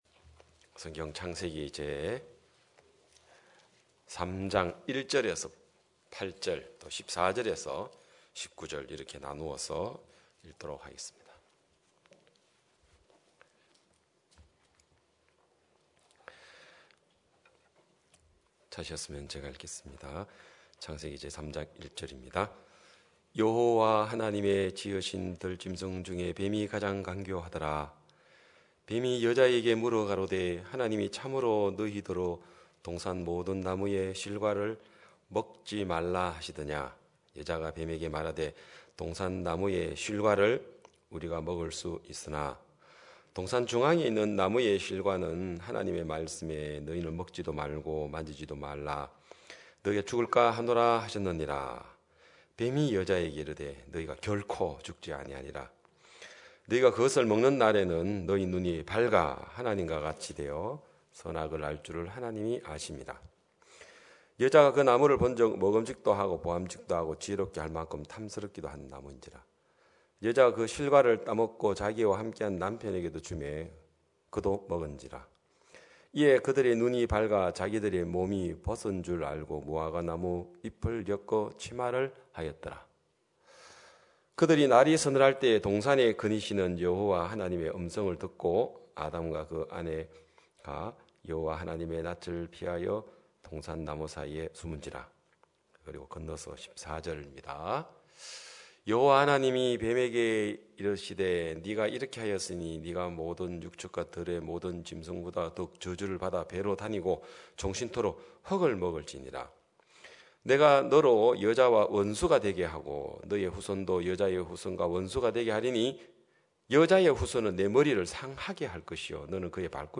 2022년 2월 27일 기쁜소식양천교회 주일오전예배
성도들이 모두 교회에 모여 말씀을 듣는 주일 예배의 설교는, 한 주간 우리 마음을 채웠던 생각을 내려두고 하나님의 말씀으로 가득 채우는 시간입니다.